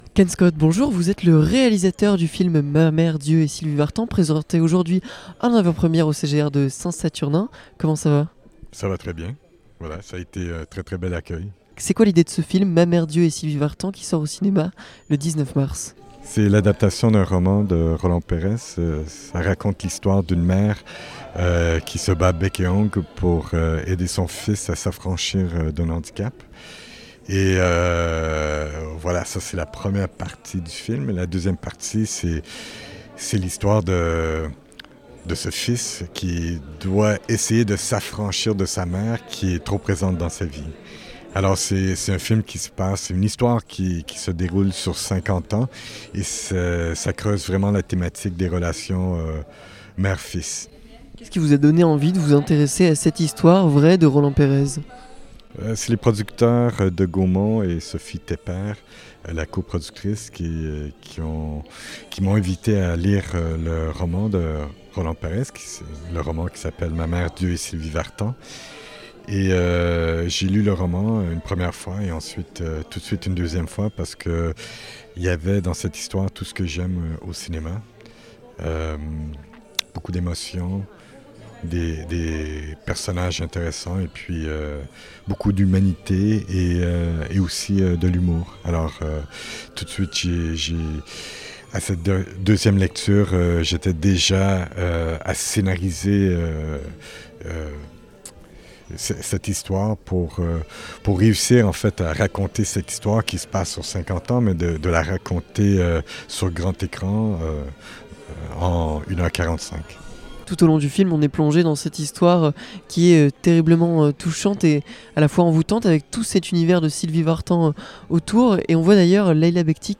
Rencontre avec Ken Scott, réalisateur de "Ma mère, Dieu et Sylvie Vartan"
Nous avons rencontré le réalisateur quelques minutes après la projection.